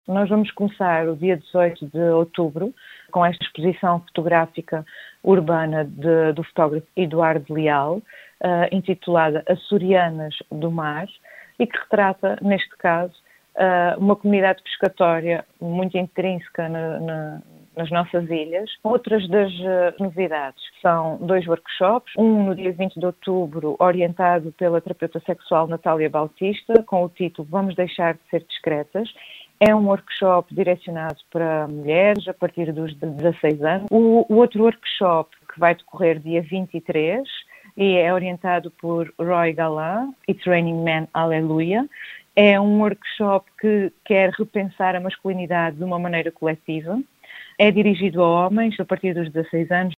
em entrevista à Atlântida